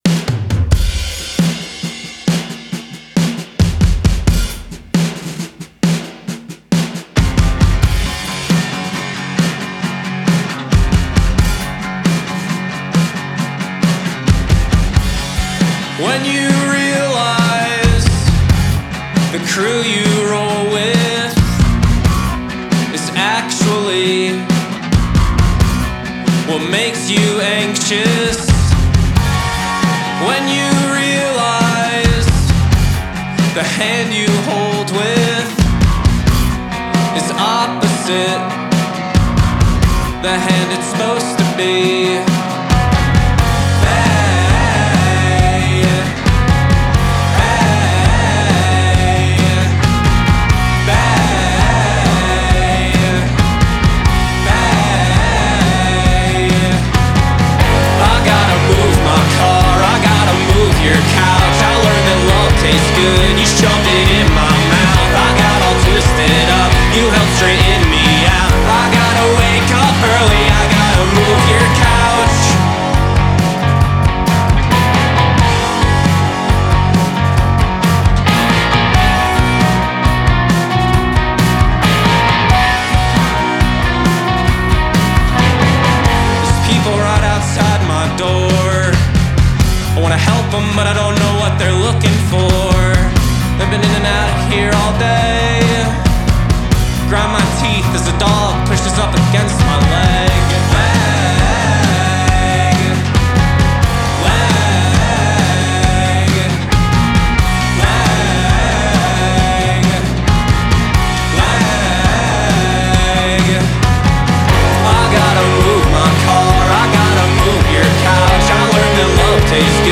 staccato groove
with its surging chorus